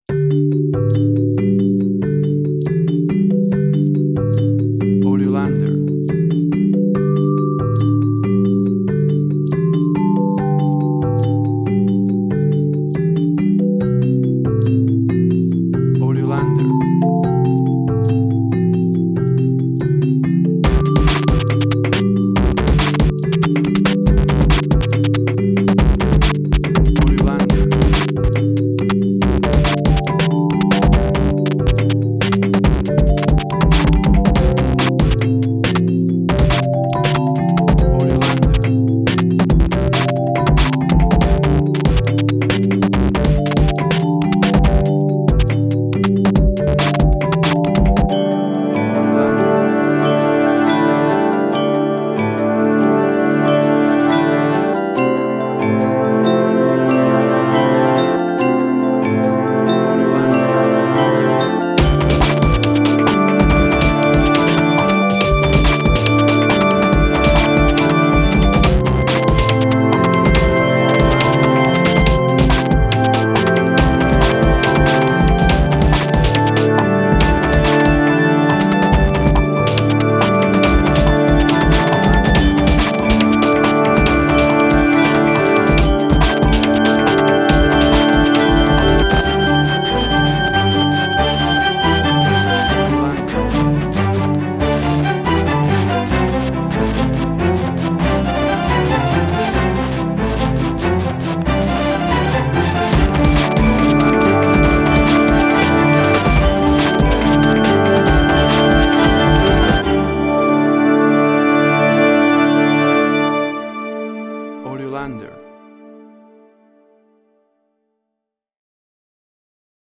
IDM, Glitch.
Tempo (BPM): 140